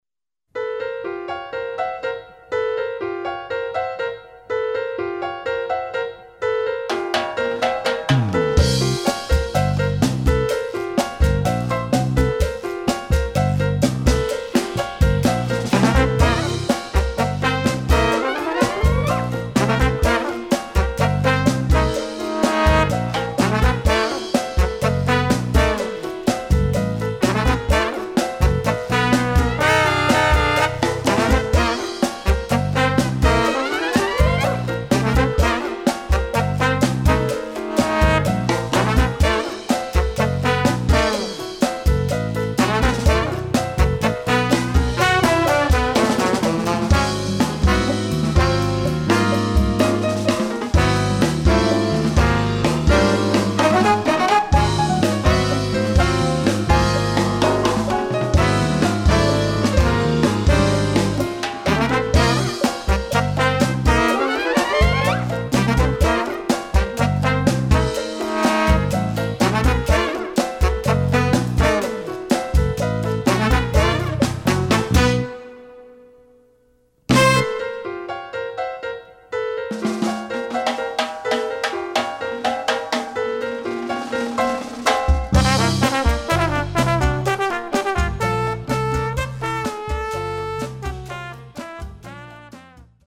Category: combo
Style: funky cha cha
Solos: open